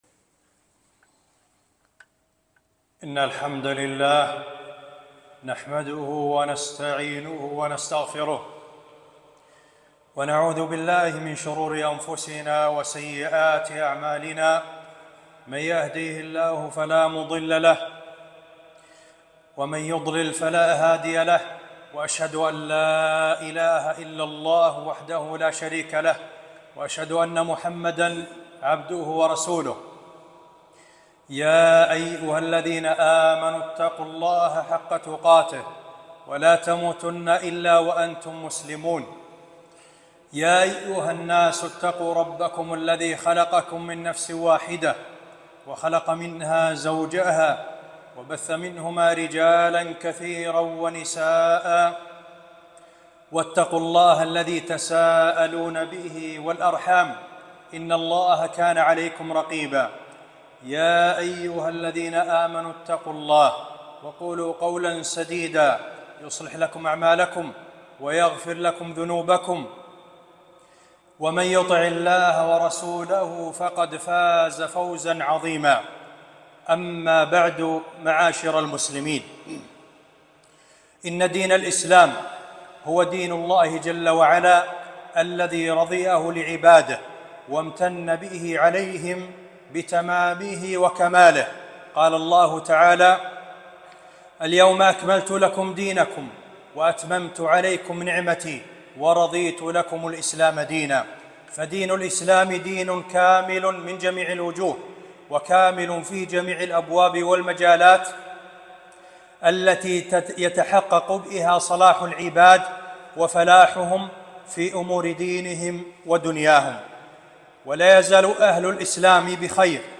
خطبة - حقوق ولاة الأمر على الرعية واجتماع الكلمة عليهم